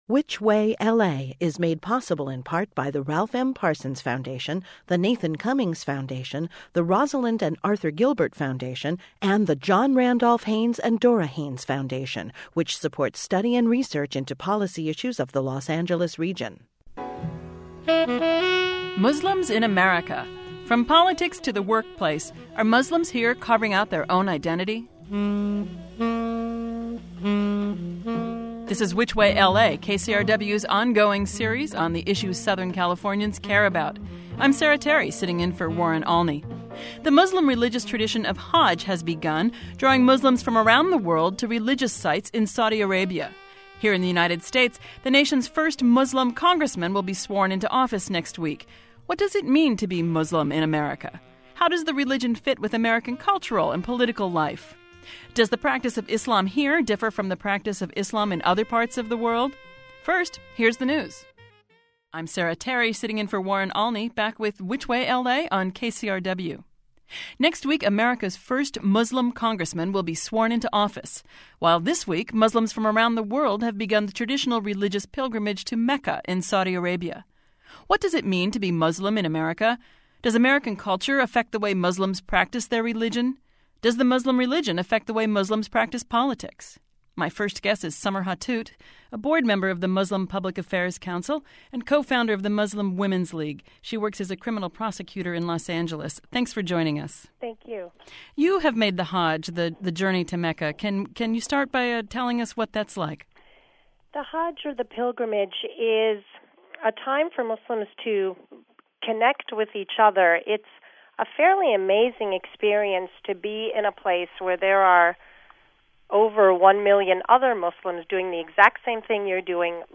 (An expanded version of this discussion was broadcast earlier today on To the Point.)